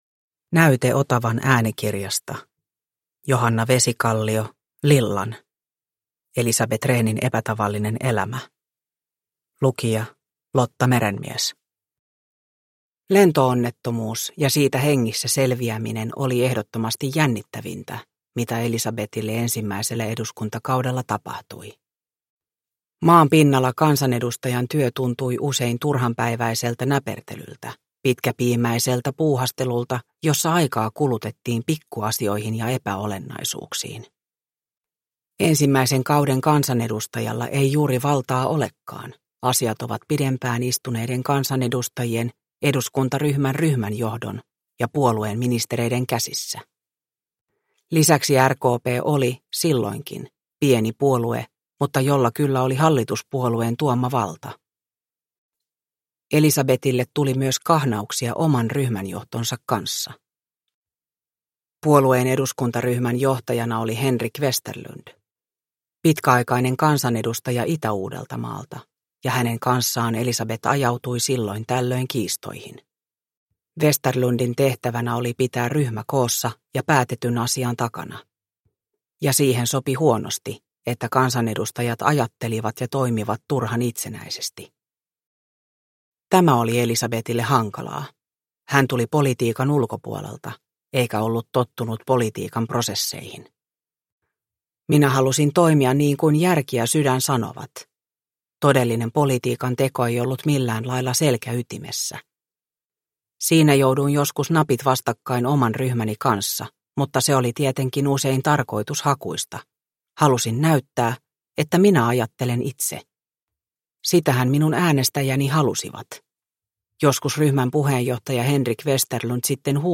Lillan – Ljudbok – Laddas ner